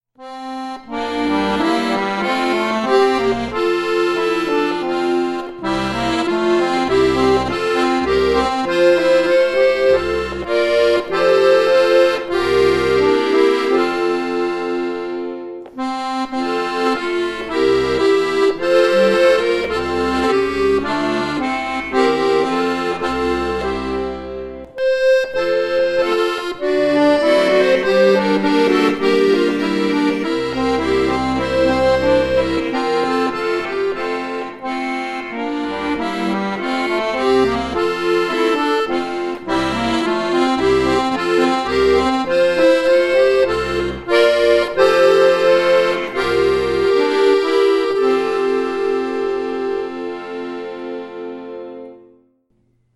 hier etwas aufwändiger harmonisiert
Weihnachtslied